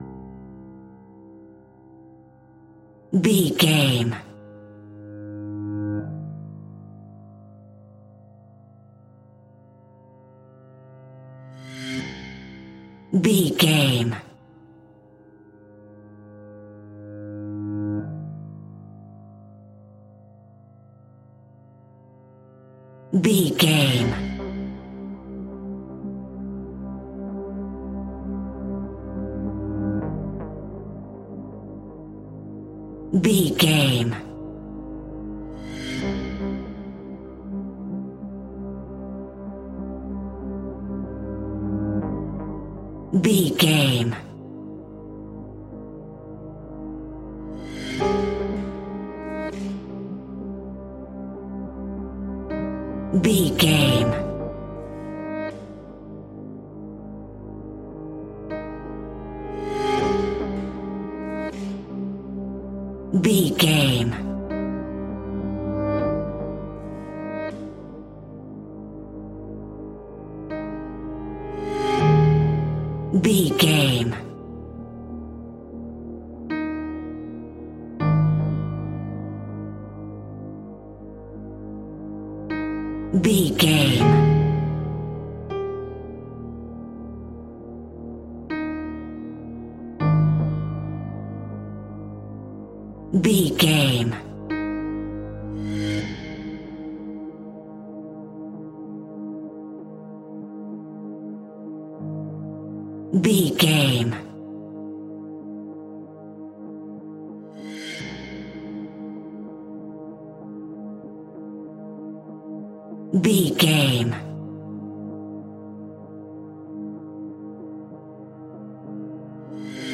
Aeolian/Minor
Slow
scary
ominous
dark
haunting
eerie
melancholy
ethereal
synthesiser
piano
strings
cello
percussion
instrumentals
horror production music